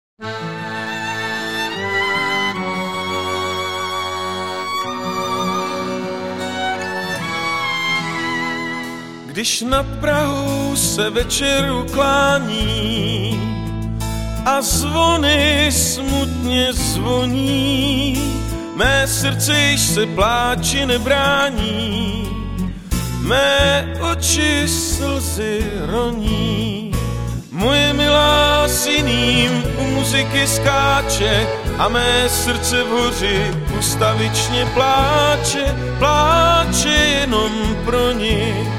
Studio A České televize (smyčce)